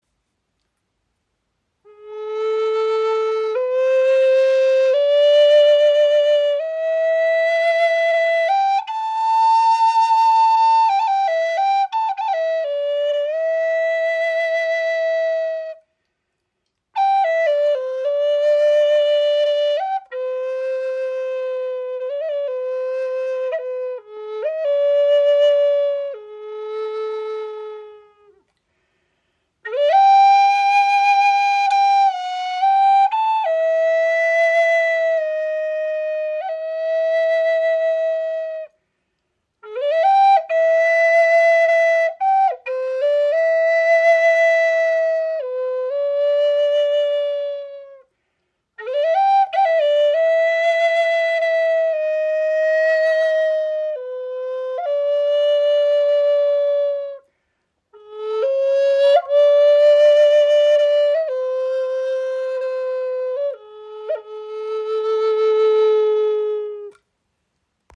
• Icon Klarer, präziser Klang – ideal auch für hohe Töne
Unsere handgefertigte Flöte in A-Moll wird aus in Amerika heimischem Tanoak-Holz gefertigt und bietet einen klaren, präzisen Klang, besonders bei hohen Tönen.
Das Tanoak-Holz glänzt vor allem bei den hohen Tönen, ohne die Klarheit der gesamten Tonleiter zu verlieren.
Sie orientieren sich an der Form und Klangästhetik traditioneller Native American Flutes, werden aber nicht von Native Americans gefertigt.